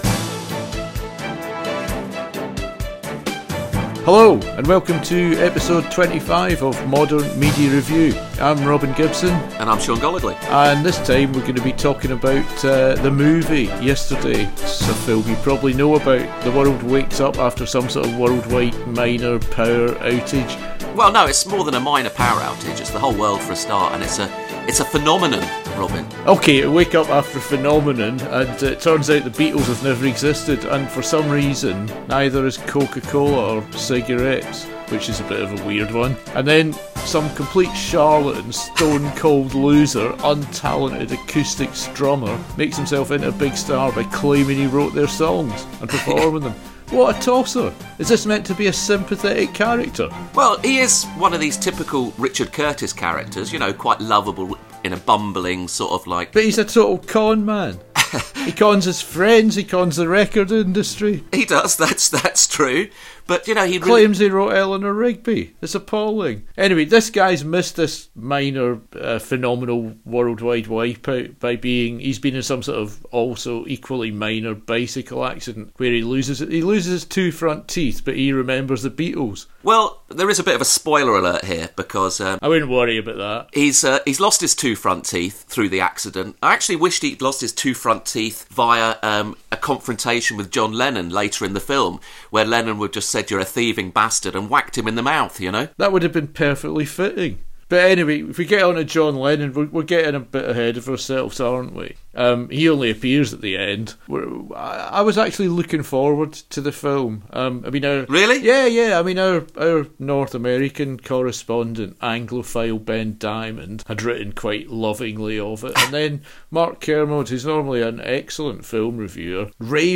Contains karaoke.